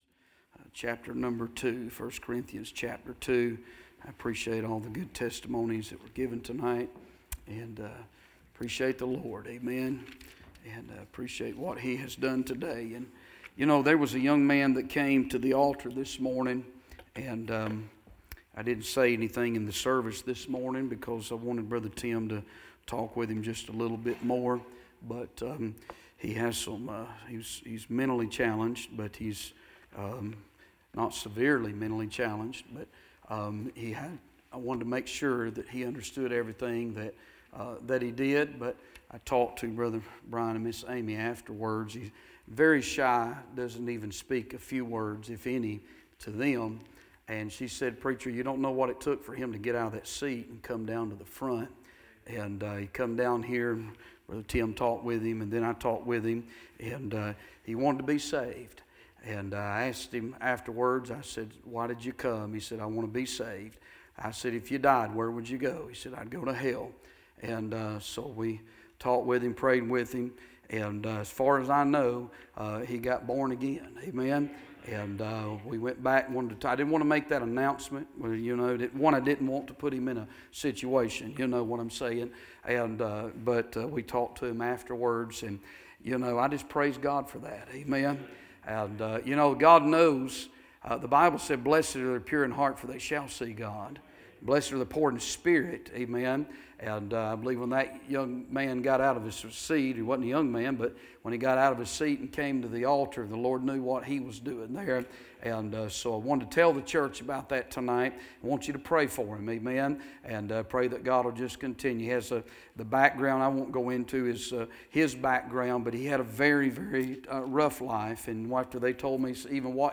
Listen to the most recent sermons from Bible Baptist Church